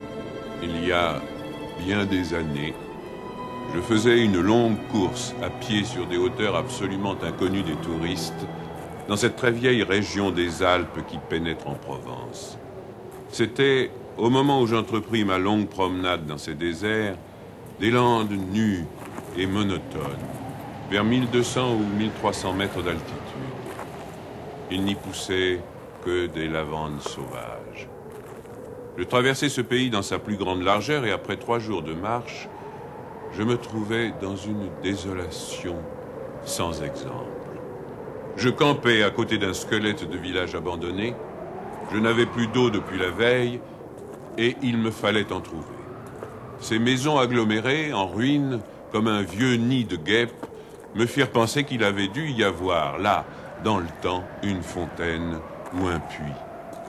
Diffusion distribution ebook et livre audio - Catalogue livres numériques
Lire un extrait Jean Giono L'homme qui plantait des arbres Coffragants Date de publication : 2009 Lu par Philippe Noiret Non loin d'un hameau ruiné par la sécheresse et balayé par les vents, un berger vit en solitaire avec ses brebis et son chien.
Cet enregistrement du texte de Jean Giono, lu par Philippe Noiret, est extrait du film produit par la société Radio-Canada et pour lequel Frédéric Back a remporté l'Oscar du meilleur court métrage d'animation, en 1998.